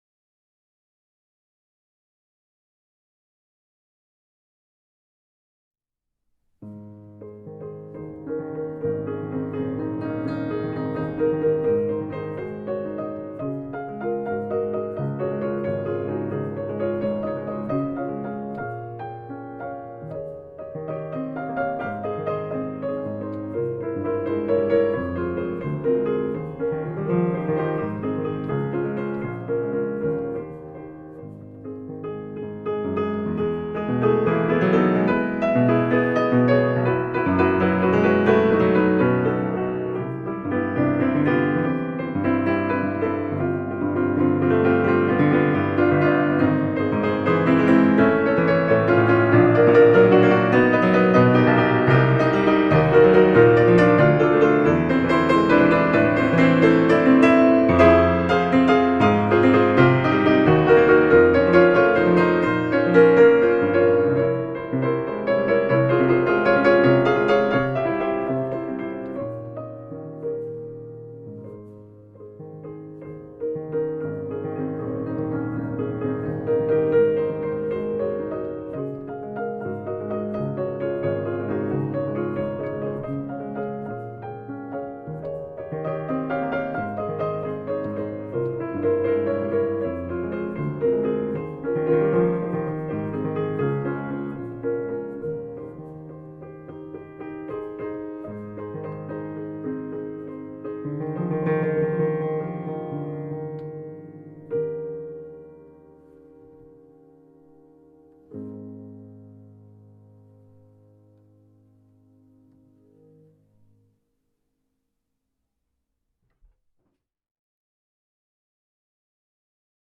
Etude in A flat Major (smusic) Chopin, Frederic 2/4 3